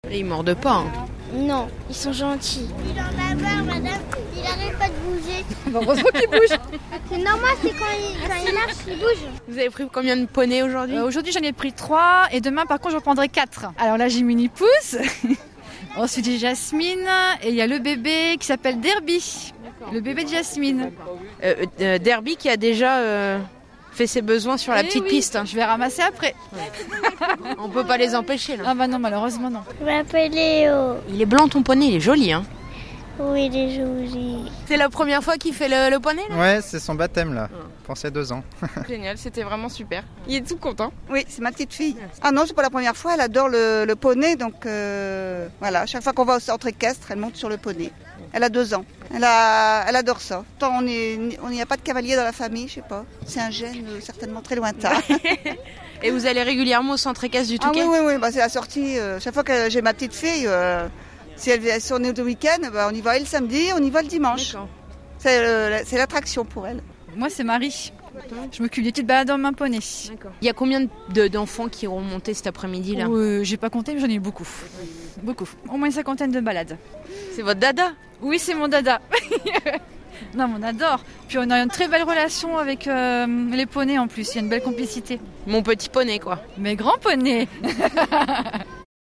reportage place du centenaire au Touquet avec des initiations de poneys.